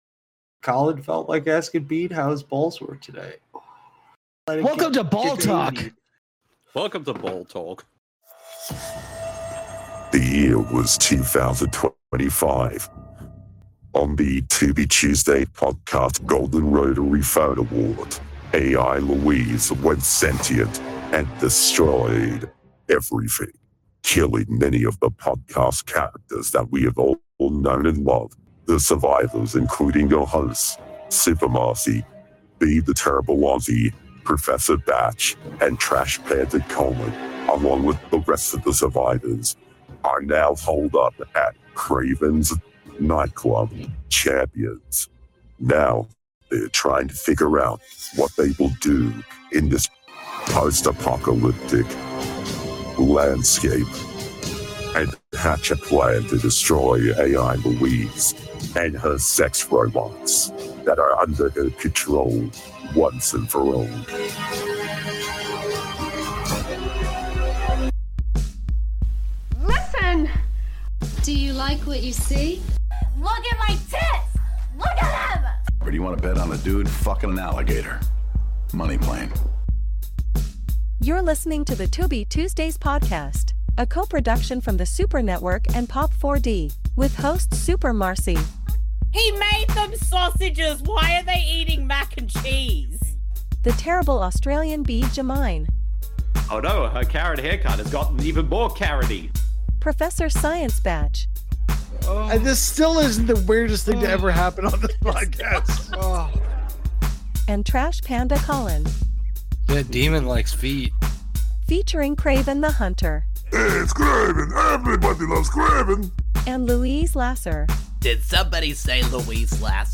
This podcast series is focused on discovering and doing commentaries/watch a longs for films found on the free streaming service Tubi, at TubiTV
Welcome back to The Tubi Tuesdays Podcast, the number one Tubi related podcast that’s hosted by two Australians, one Canadian and one American!